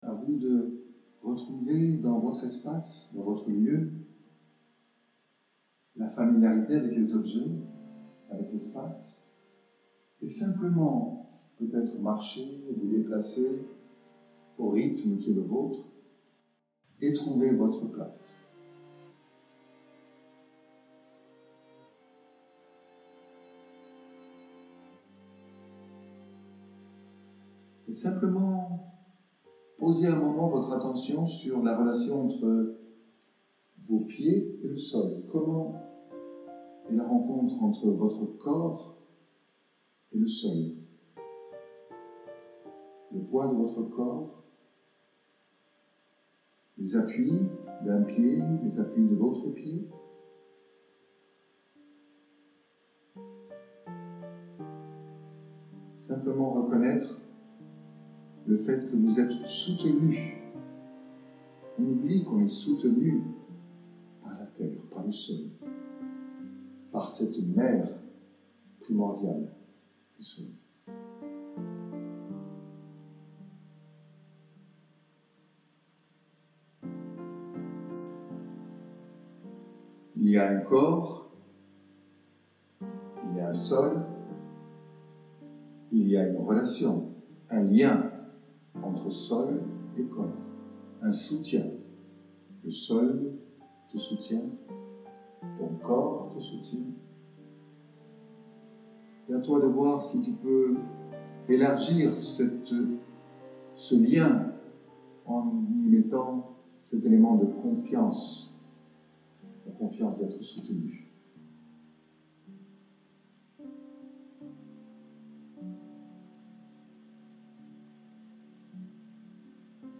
Pour vous ressourcer et vous aider à créer des espaces de détente et d’introspection dans votre quotidien, voici quelques méditations et visualisations
processus-hoffman-audios-eveil-corporel-centrage-et-mouvement.mp3